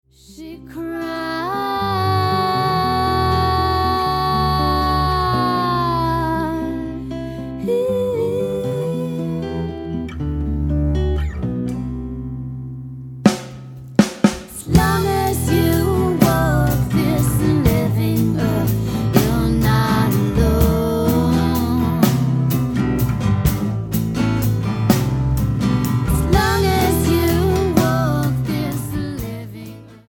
Alternative,Blues,Folk,New Age